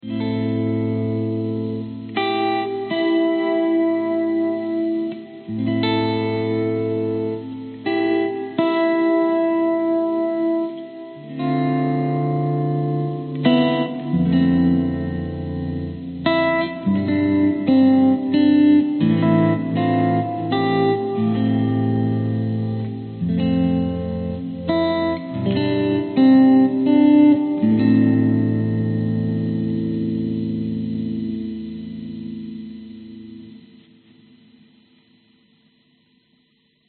Am85 GTR VolumeP...
描述：原声吉他+音量踏板，应用了信号处理。
Tag: 原声 寒蝉 吉他 音量_踏板